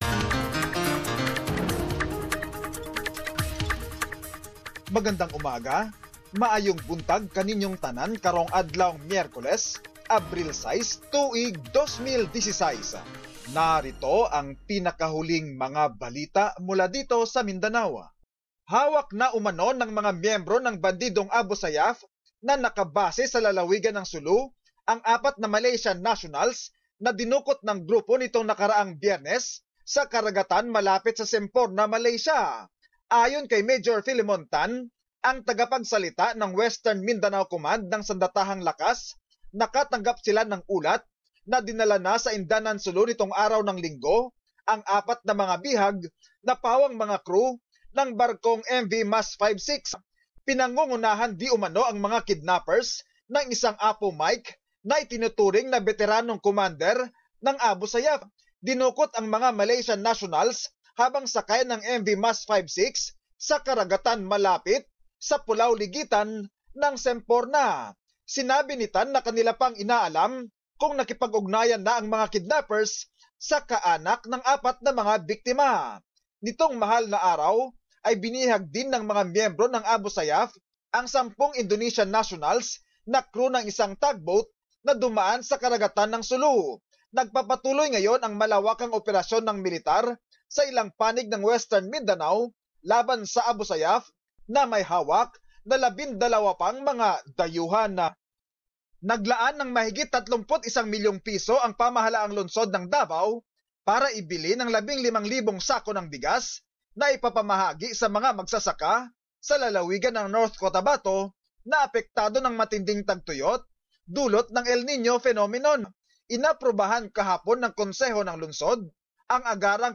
Summary of latest news from the region